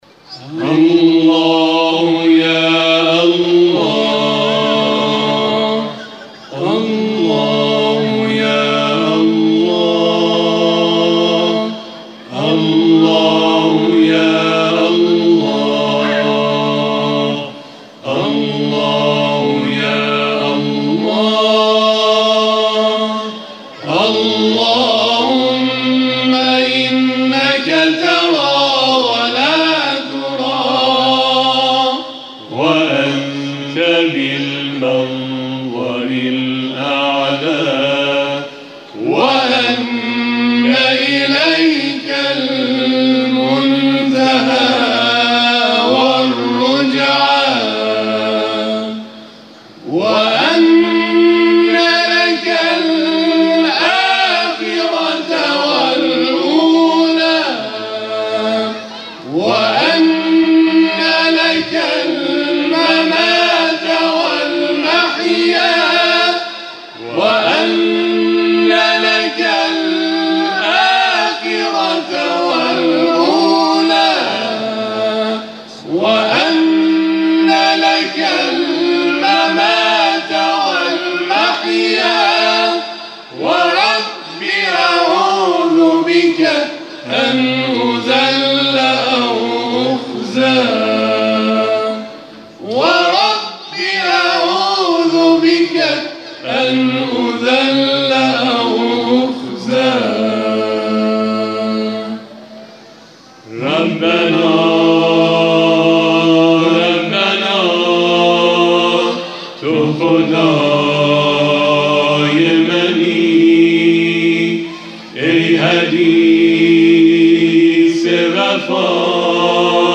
در پایان، اجرای شب گذشته این گروه تواشیح در محفل انس با قرآن کریم ویژه ماه مبارک رمضان در مجتمع فرهنگی سرچشمه ارائه می‌شود.
برچسب ها: گروه تواشیح ، جلسه قرآن ، محفل انس با قرآن ، گروه تواشیح نور